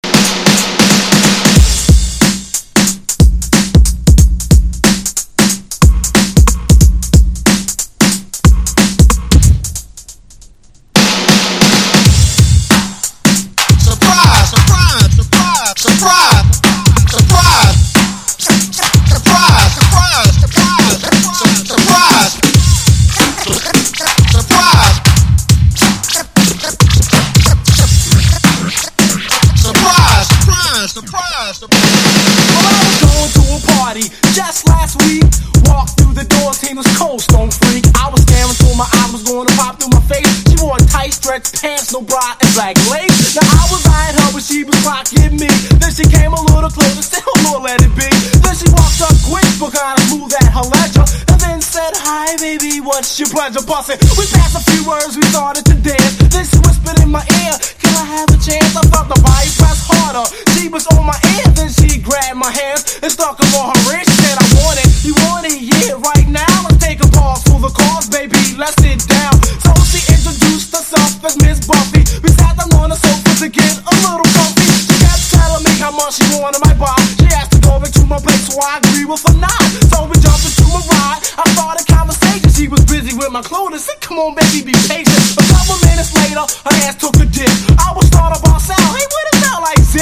フィルターのかかったラップと金属的なドラムは今では絶対に作られていないサウンド！